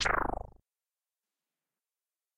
pound.ogg